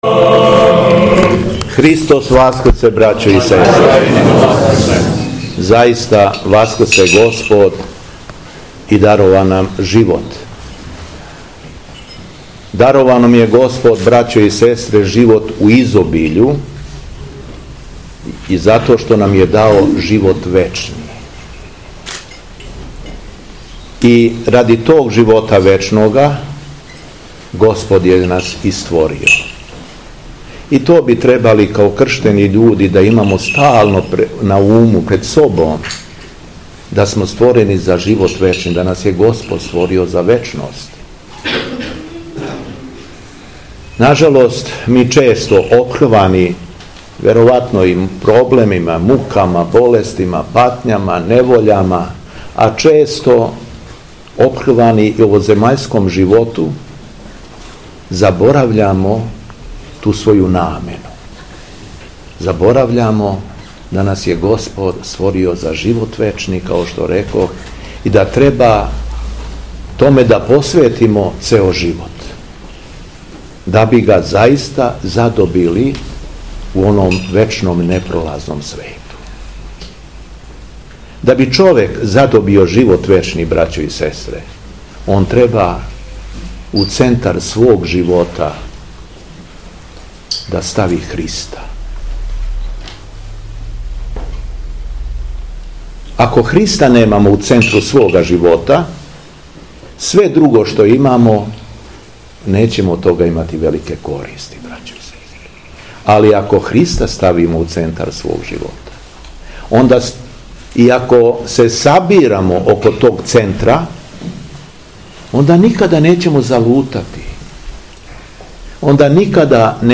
ОСВЕЋЕЊЕ ЗВОНА И СВЕТА АРХИЈЕРЕЈСКА ЛИТУРГИЈА У БАГРДАНУ КОД ЈАГОДИНЕ - Епархија Шумадијска
Беседа Његовог Преосвештенства Епископа шумадијског г. Јована